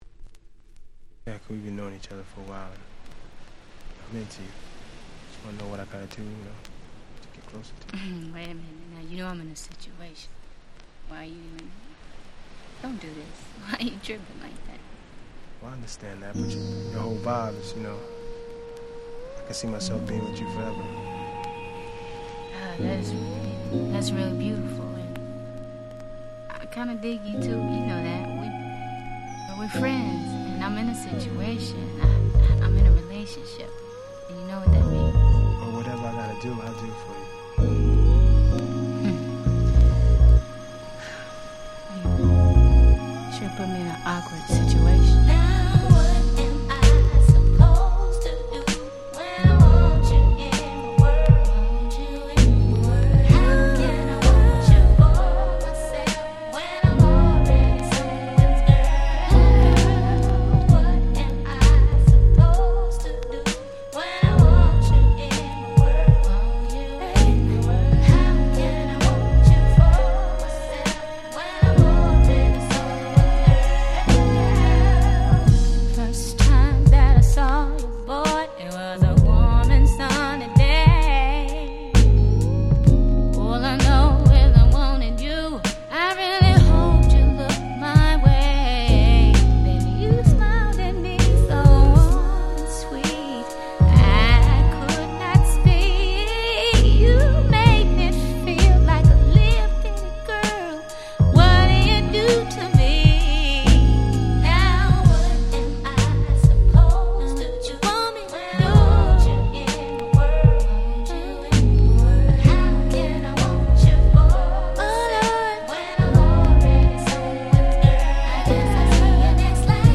97' Very Nice R&B / Neo Soul !!
ネオソウル